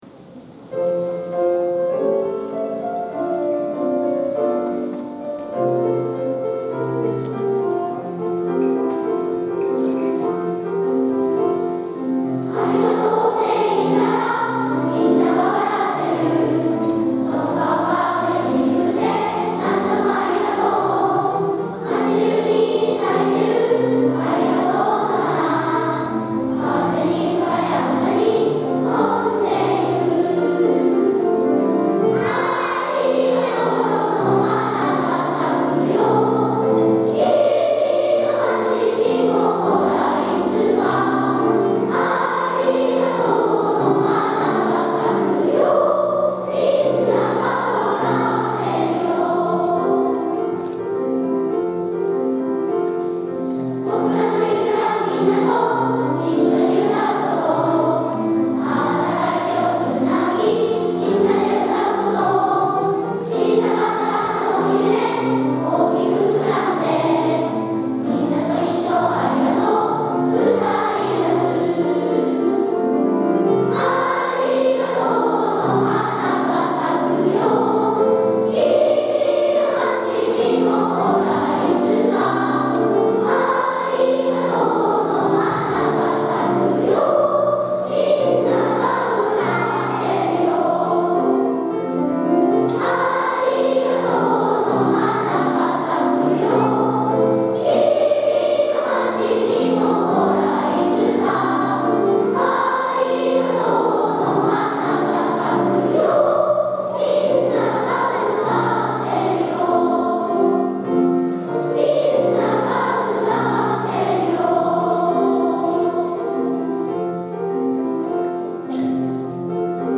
2018年2月24日（土）大空ありがとうコンサート
コンサートも終わりに近づき、大空小のみんなで歌う全校合唱です。